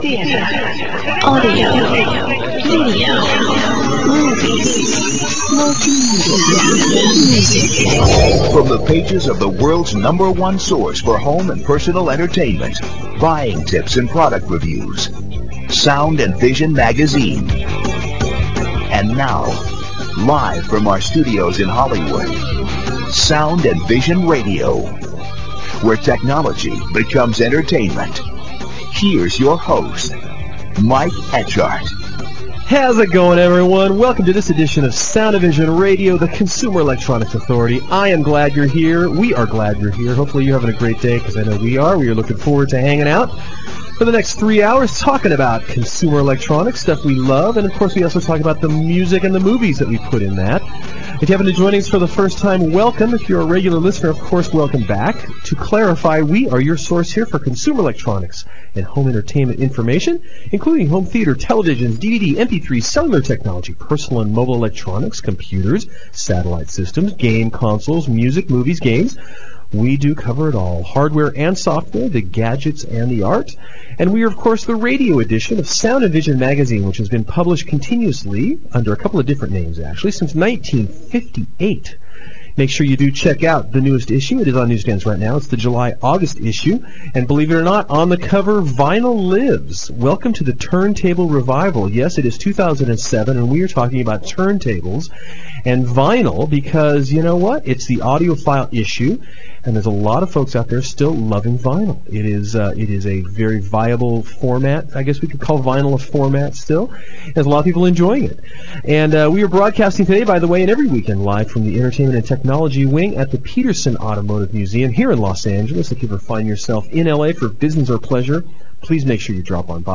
Bob Mosley interview - Bluoz